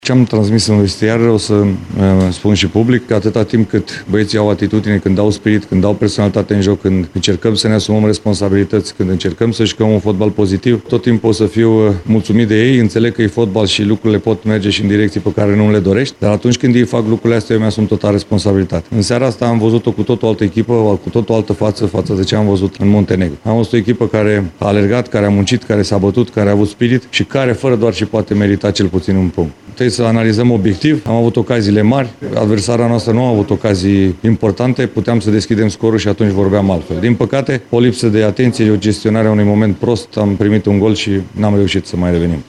După meci, selecționerul Edward Iordănescu s-a declarat mulțumit de atitudinea elevilor săi de la Zenica și a spus că rămâne optimis și că echipa națională are viitor: